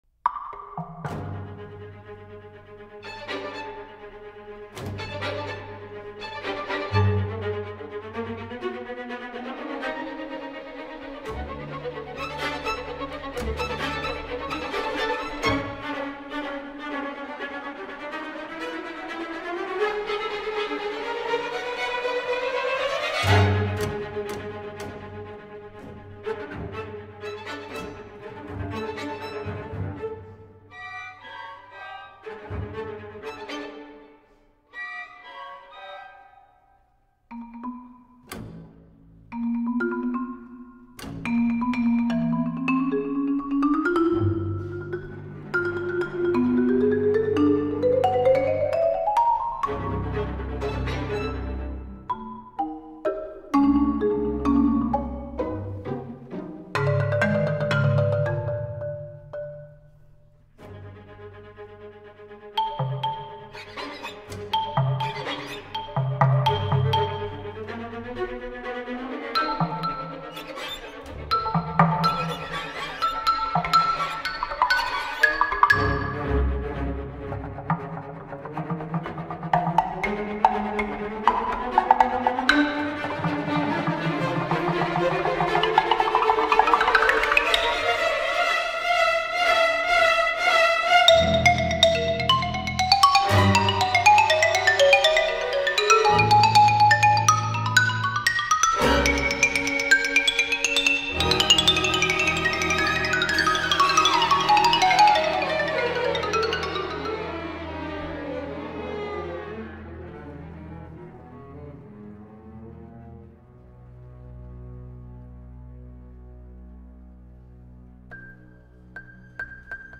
2013 GRAMMY Winner: Best Classical Instrumental Solo